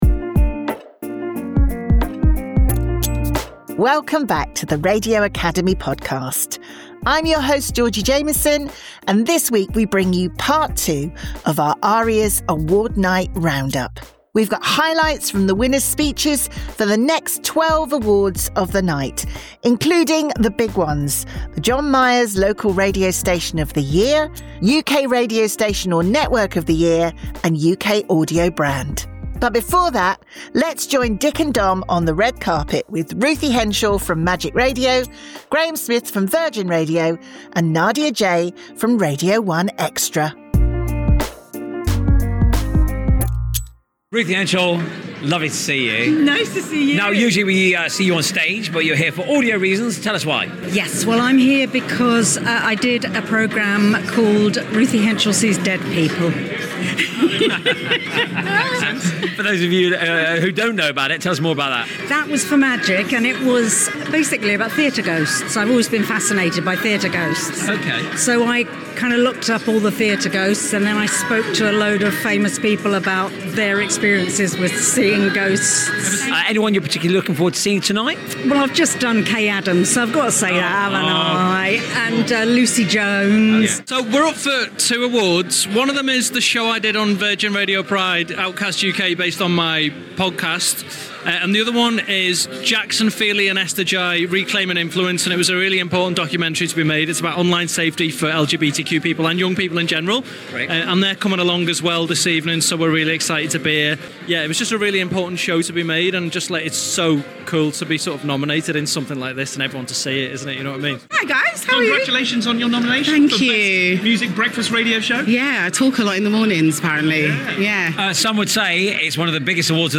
Headliner Embed Embed code See more options Share Facebook X Subscribe This week we have part 2 of our Arias Award night roundup with highlights from the winners speeches and backstage interviews. This batch of winners includes Best Sports award, the Radio Times Moment of the Year and the big ones, the John Myers Local Radio Station of the Year, UK Radio Station or Network of the Year and UK Audio Brand.